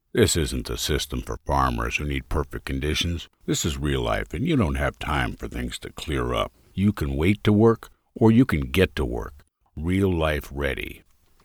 0525Cowboy-Wise_Demo.mp3